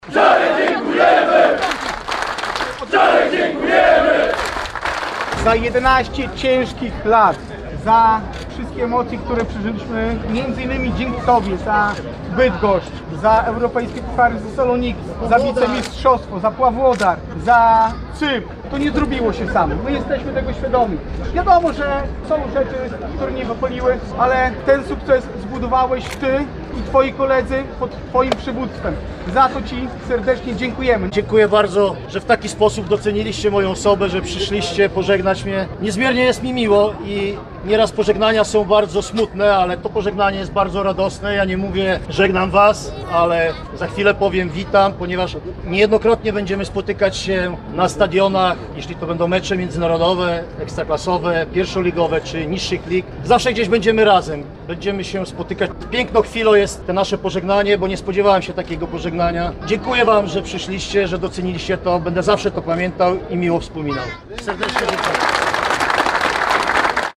Fani drużyny spotkali się z Cezarym Kuleszą na Rynku Kościuszki w Białymstoku, aby podziękować mu za lata piłkarskich emocji i zaangażowanie w rozwijanie Jagiellonii.